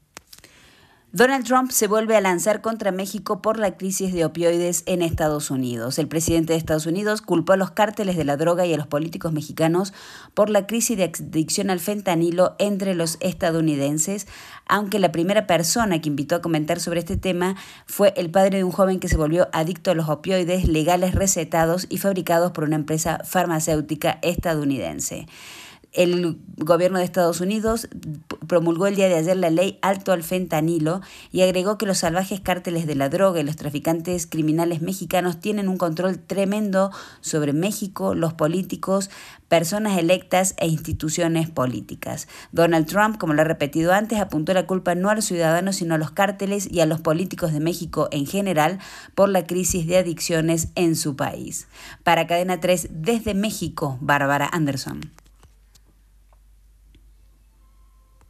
Trump acusa a México por la crisis de opioides y el aumento del fentanilo - Boletín informativo - Cadena 3 - Cadena 3 Argentina
La primera voz en comentar sobre el tema es la de un padre cuyo hijo se volvió adicto a los opioides legales recetados, fabricados por una empresa farmacéutica estadounidense.